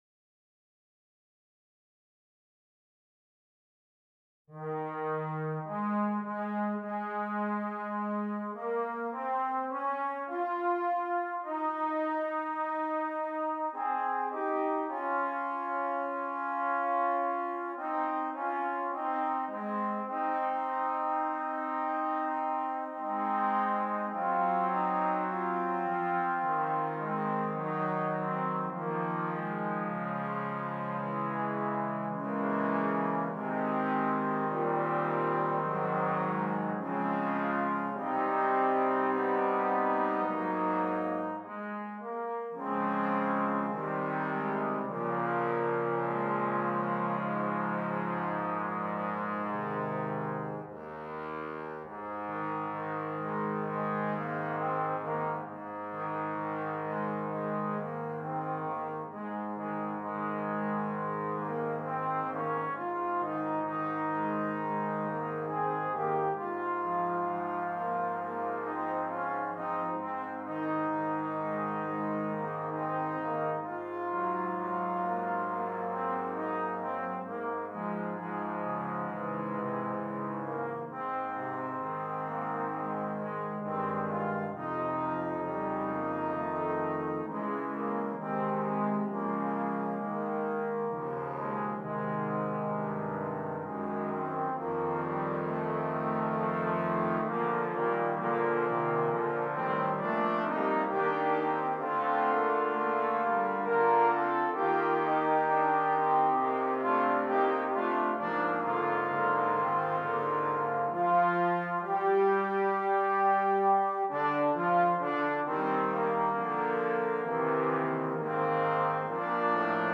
Shenandoah      Traditional American Folk Songs
This setting evokes the feeling of a ship traversing one of America s major waterways.
• 4 Trombones